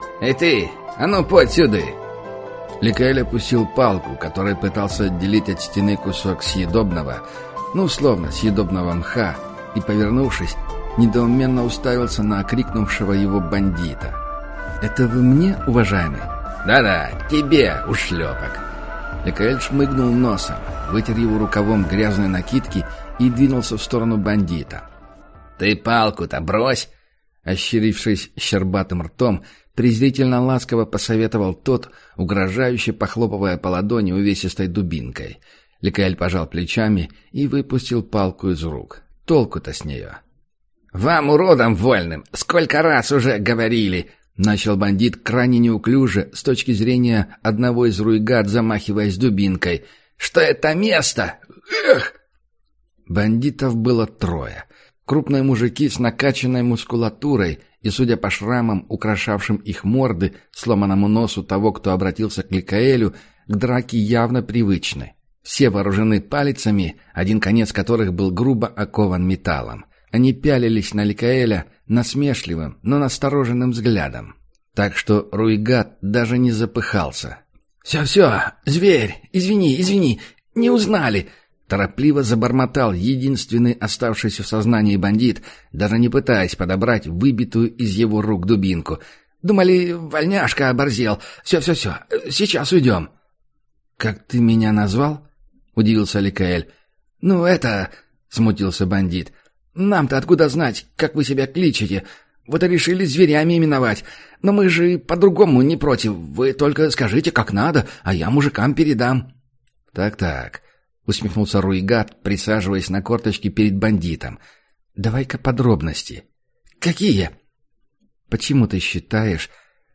Аудиокнига Руигат. Схватка | Библиотека аудиокниг